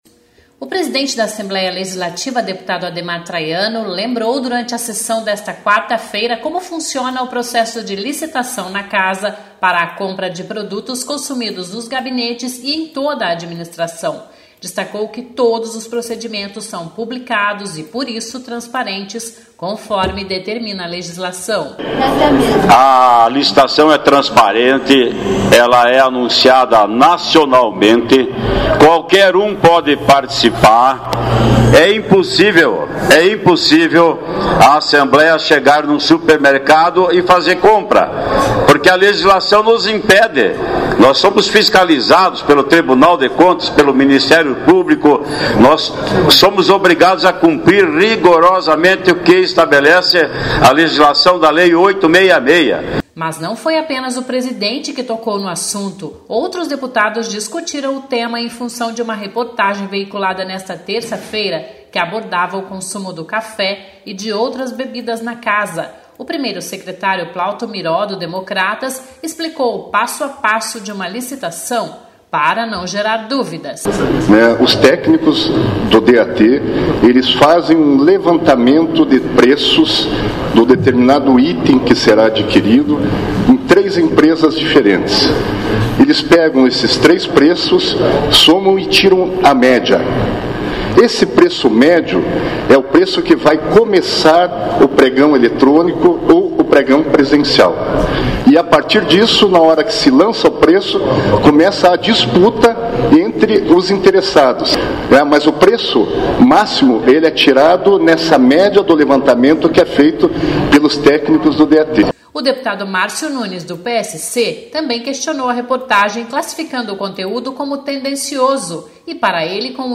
O presidente da Assembleia Legislativa,  deputado Ademar Traiano (PSDB), lembrou, durante a sessão desta quarta-feira (15) como funciona o processo de licitação na Casa para a compra de produtos consumidos nos gabinetes e em toda a administração.
O deputado Pastor Edson Praczyk (PRB), usou a tribuna para expressar a indignação e chamou a reportagem  de    equivocada.